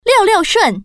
Index of /hunan_feature2/update/1271/res/sfx/common_woman/